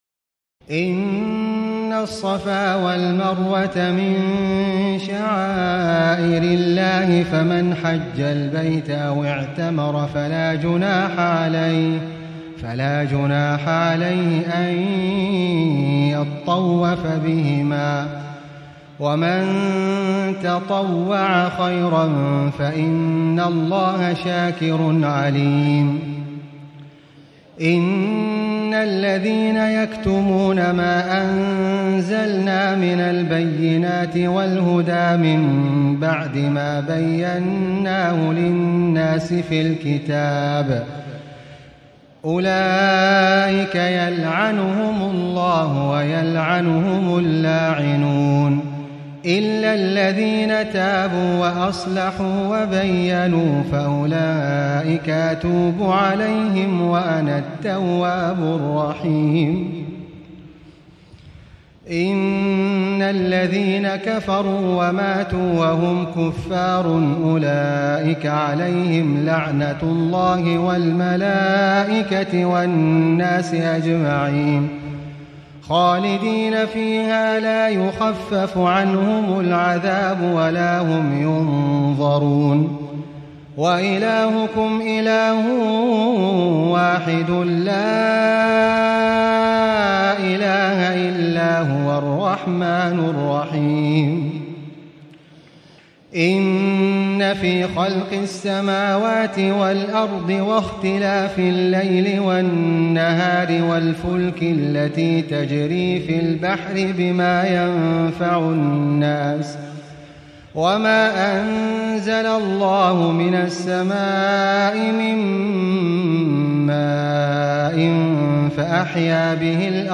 تراويح الليلة الثانية رمضان 1437هـ من سورة البقرة (158-224) Taraweeh 2 st night Ramadan 1437 H from Surah Al-Baqara > تراويح الحرم المكي عام 1437 🕋 > التراويح - تلاوات الحرمين